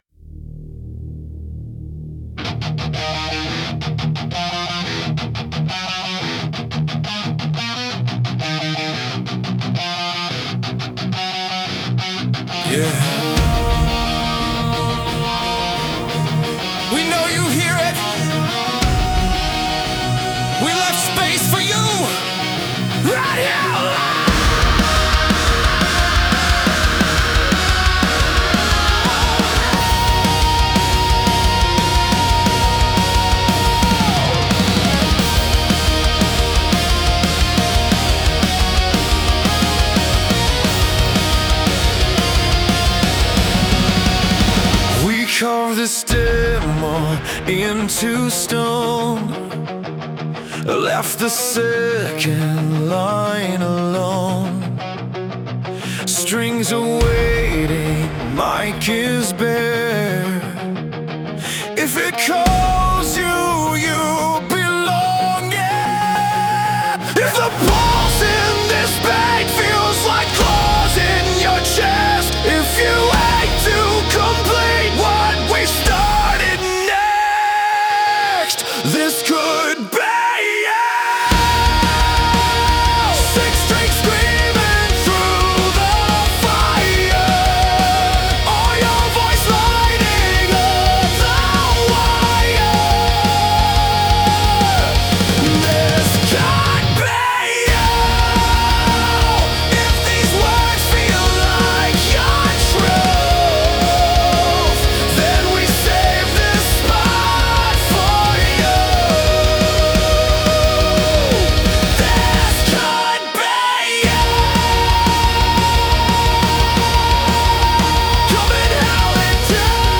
Demo Song (with vocals):